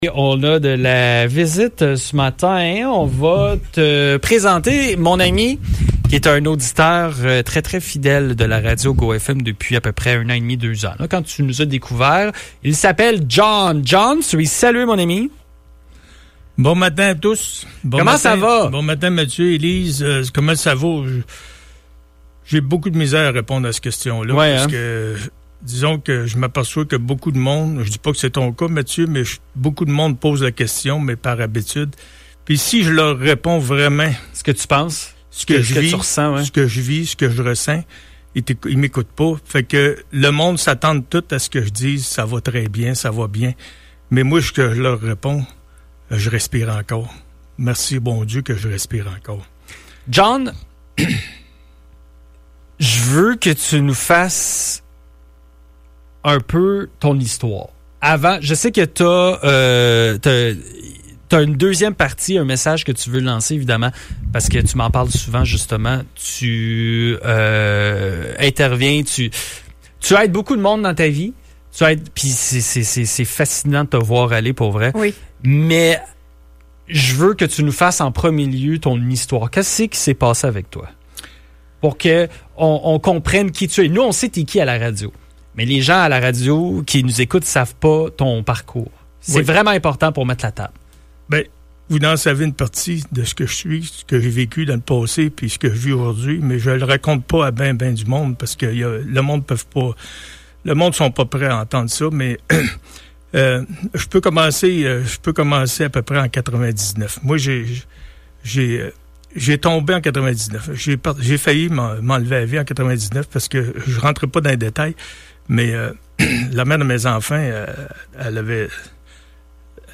Cette entrevue vise à sensibiliser à l’importance de ne pas juger trop vite, d’écouter davantage et de faire preuve d’empathie au quotidien.